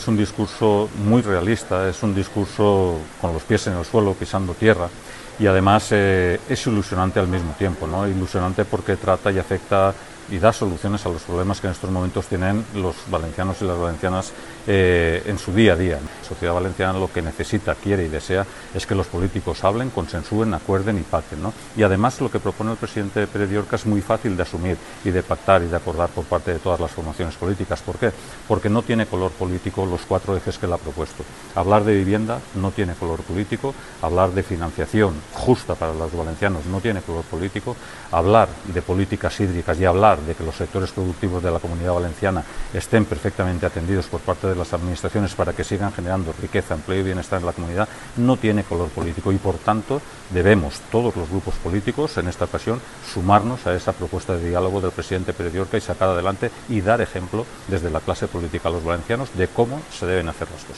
Audio-Nando-Pastor-valoracion-discurso.mp3